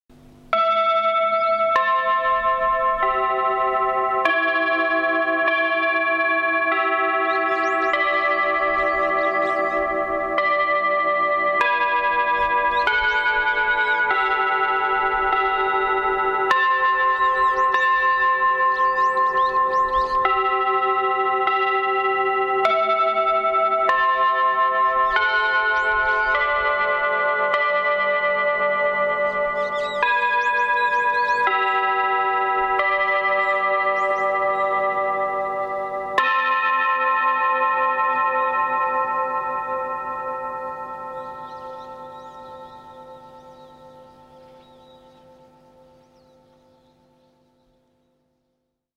旧、小須戸町時代のチャイム
chime.mp3